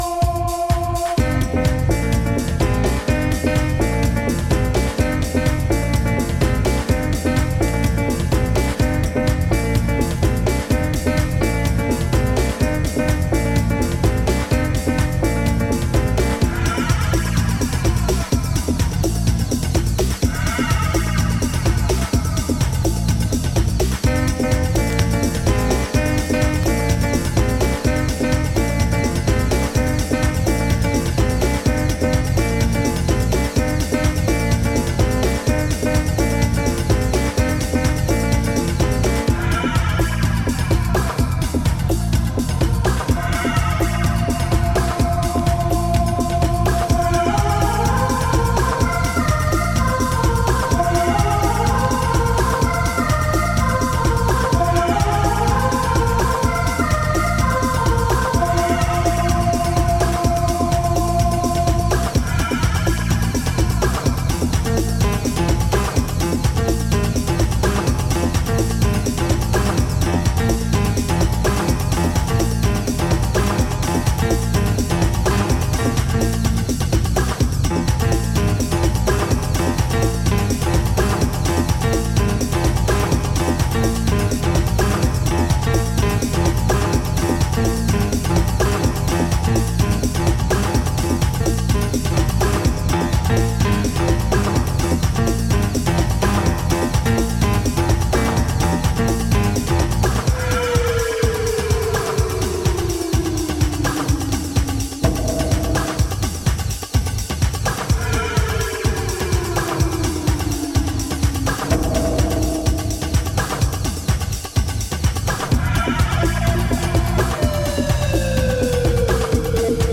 ジャンル(スタイル) DEEP HOUSE / CLASSIC HOUSE / BALEARIC HOUSE